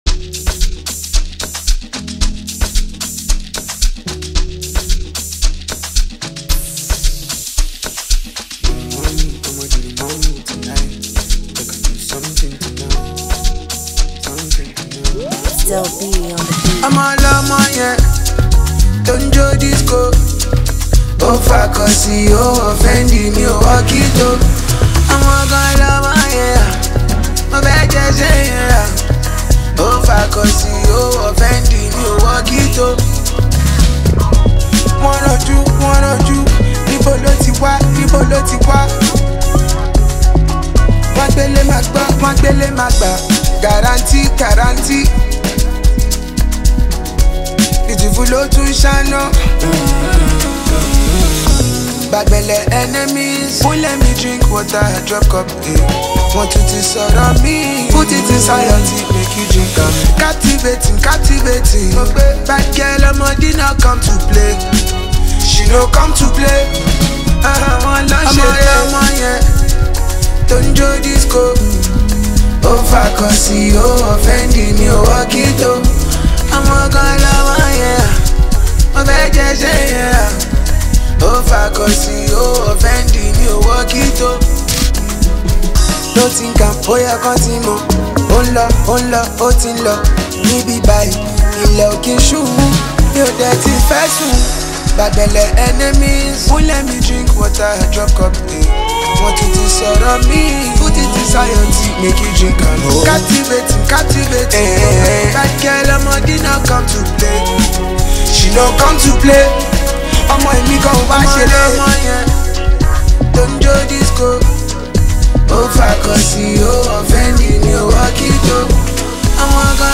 amapiano-infused song